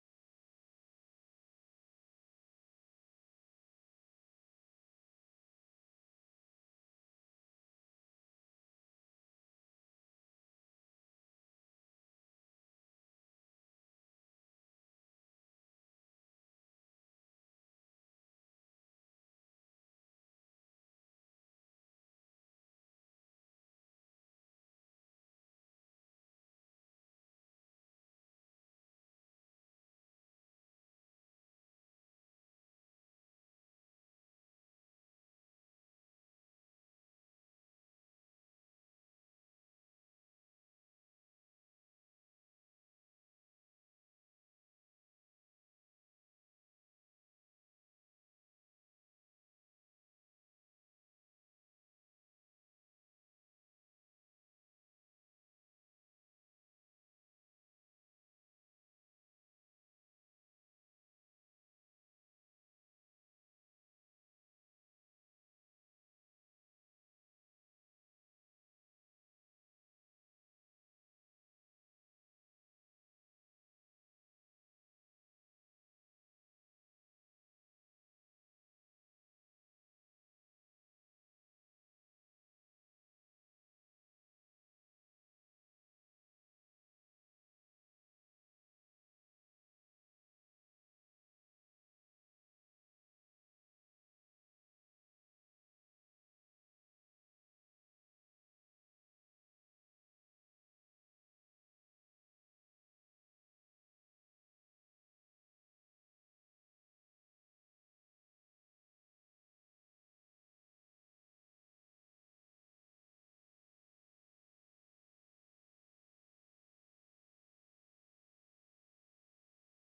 Abraza la libertad | Sermón | Iglesia Bíblica de la Gracia